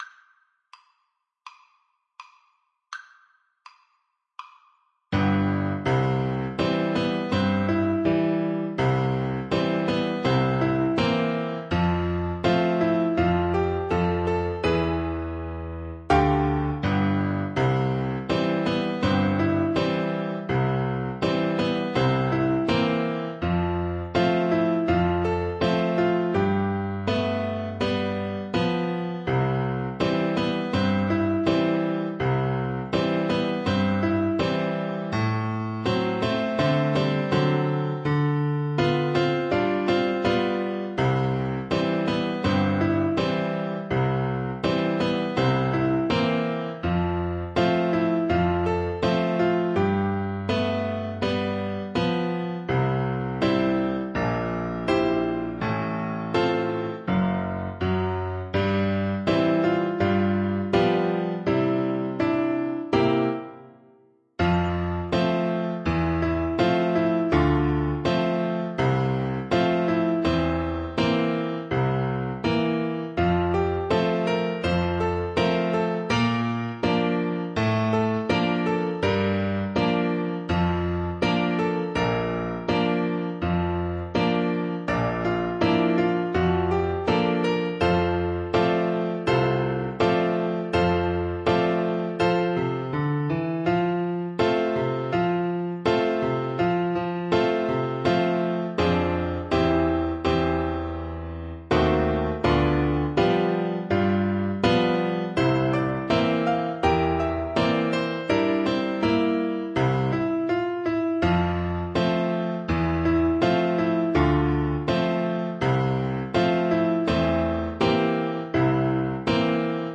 Jazz (View more Jazz French Horn Music)
Ragtime Music for French Horn